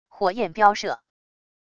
火焰飚射wav音频